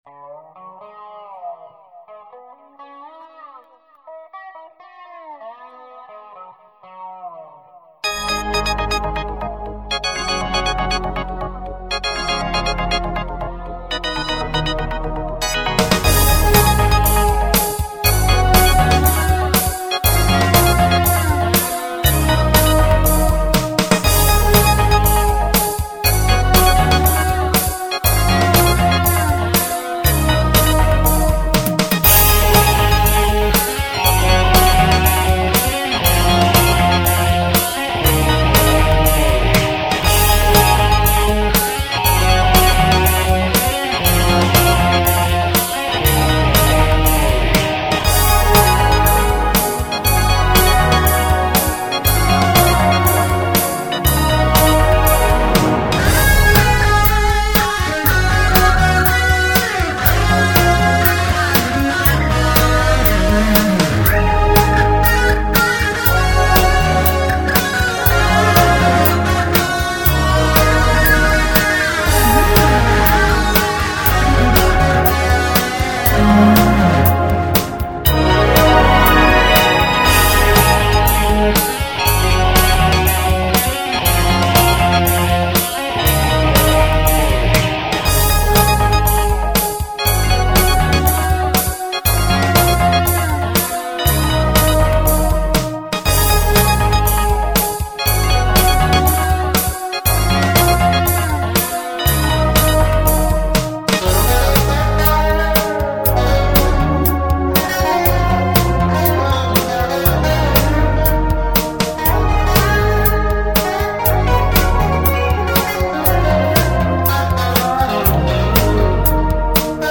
Sinon, je ne l'ai utilisée que dans une seule compo, pour le chorus du début que tu peux charger
JMG_Fretless.mp3